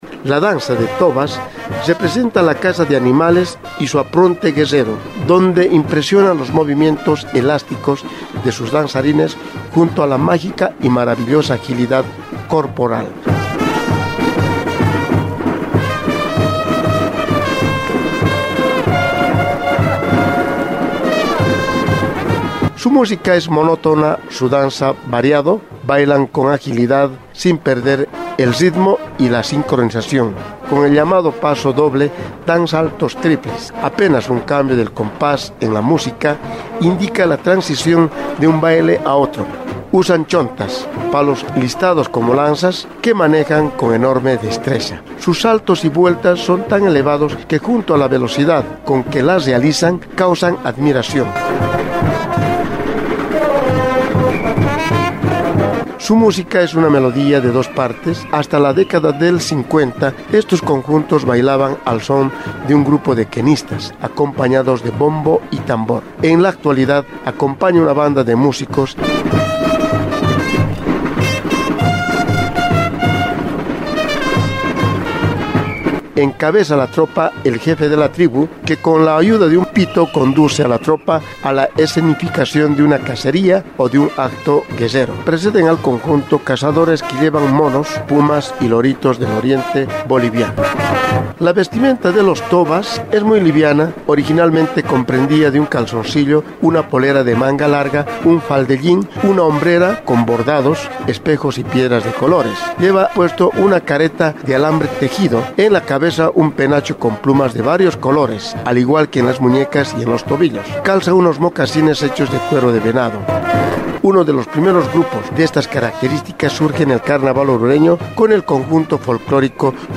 Carnaval de Oruro
La música es monótona, pero el baile es variado.
Con el llamado «paso doble», dan saltos triples, y apenas un cambio de compás en la música indica la transición de un baile a otro.